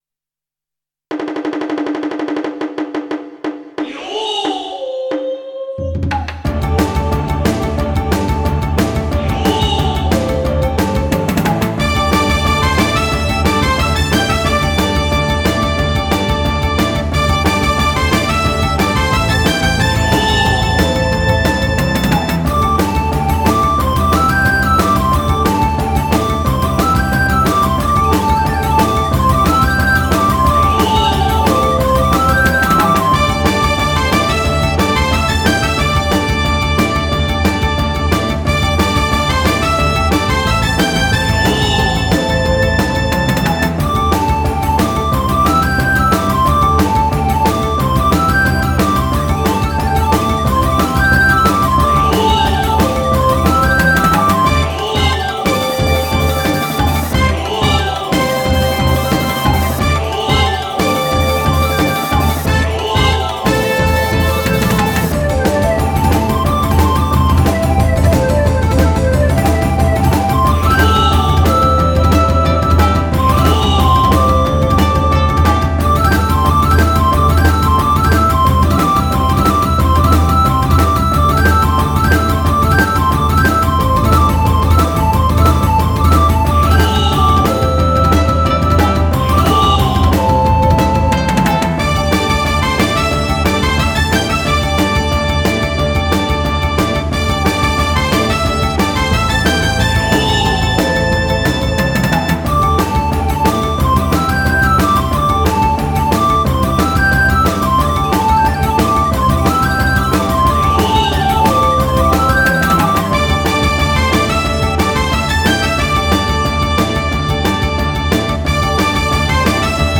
神社などでやっている派手な祭りをイメージした曲。